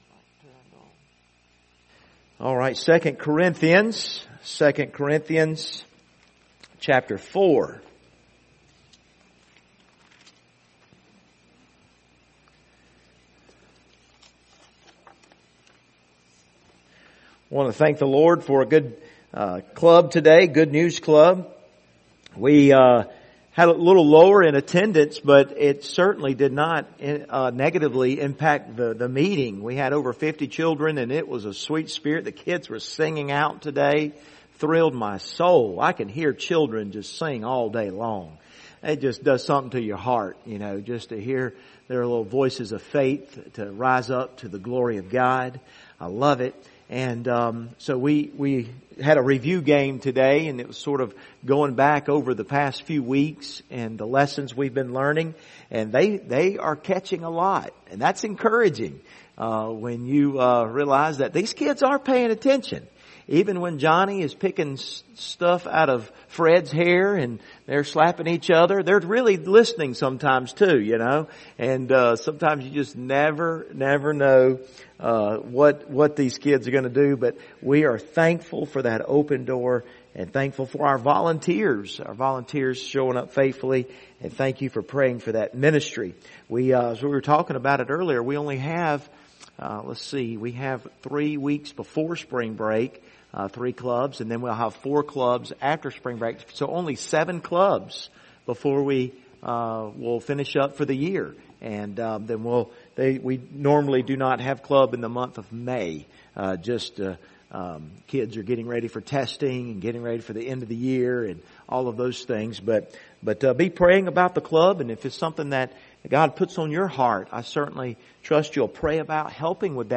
The Clay Pot Conspiracy Passage: 2 Corinthians 4:7 Service Type: Wednesday Evening « Let’s Stick Together Why Don’t They Like Me?